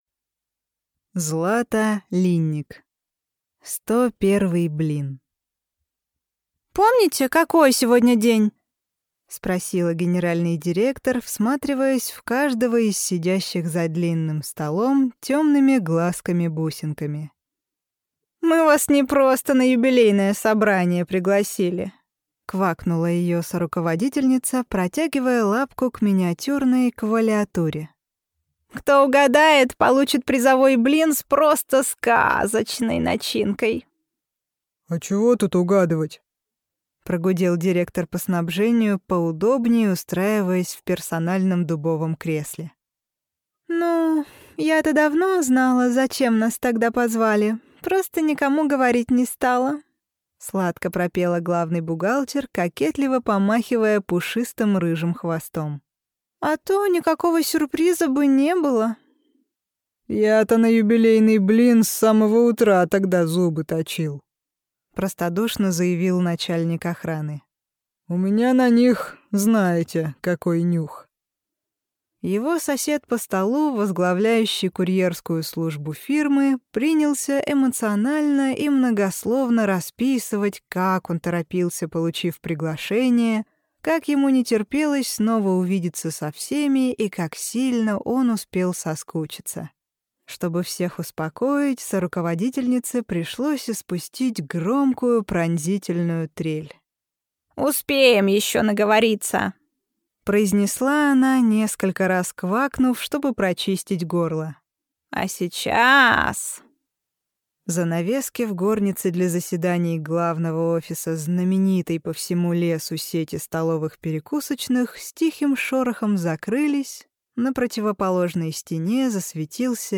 Аудиокнига Сто первый блин | Библиотека аудиокниг